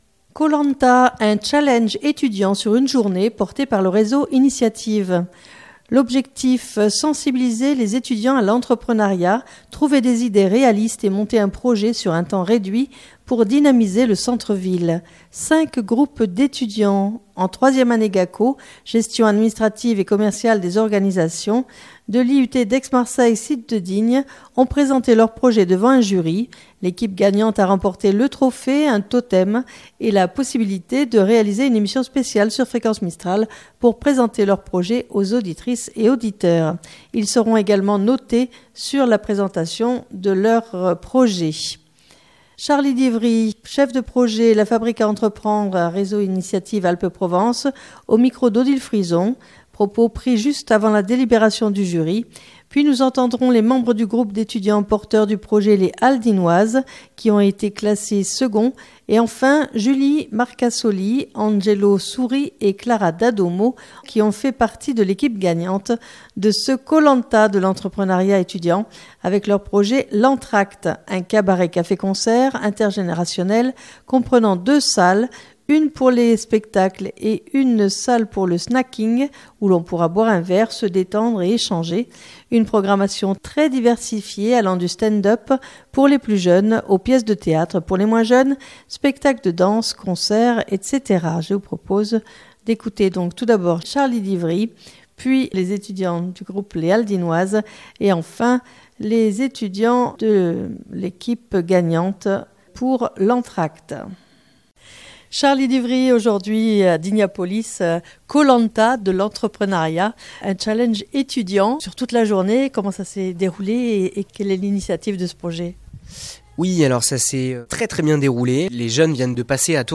Propos pris juste avant la délibération du jury.